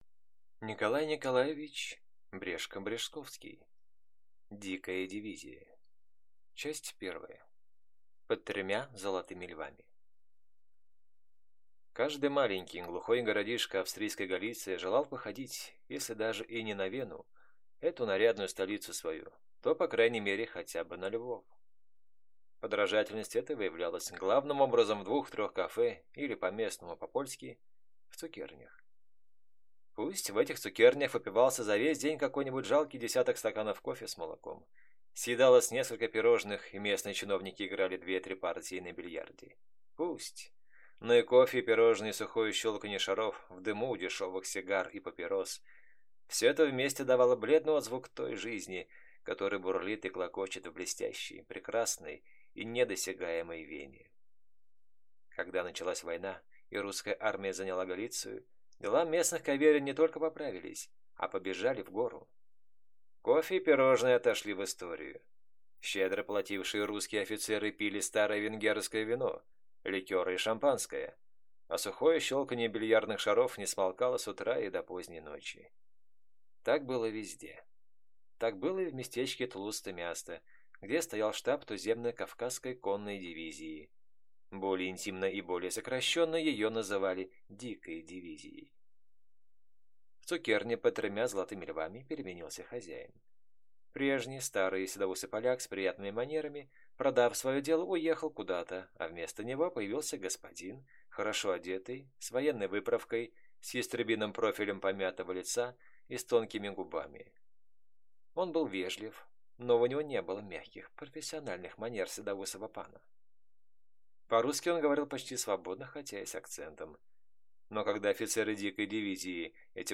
Аудиокнига Дикая дивизия | Библиотека аудиокниг